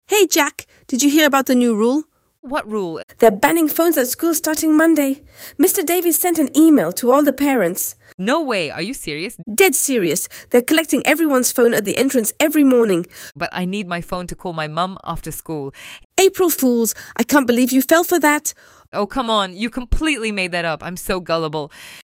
Vocabular, phrasal verbs (fall for, pull off, make up, find out), dialog autentic între doi prieteni, expresia „to pull someone's leg" și tradiții din 7 țări.
ElevenLabs_The_Big_Prank_EduMNC.mp3